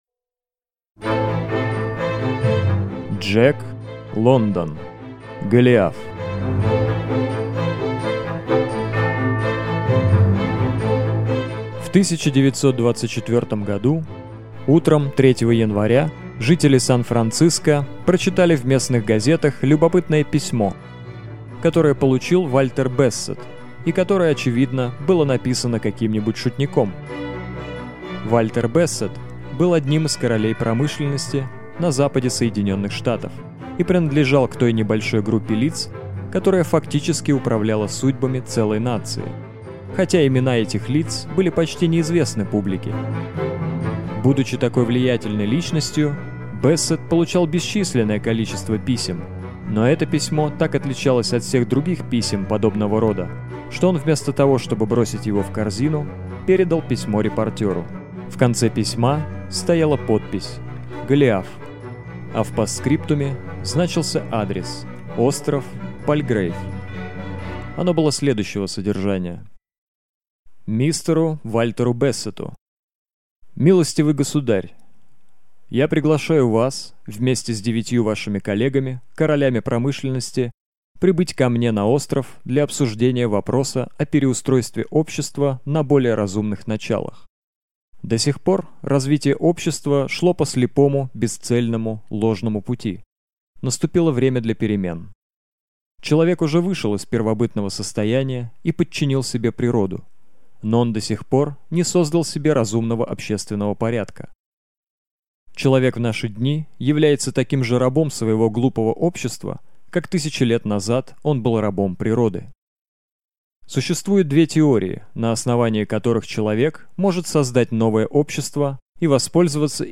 Аудиокнига Голиаф | Библиотека аудиокниг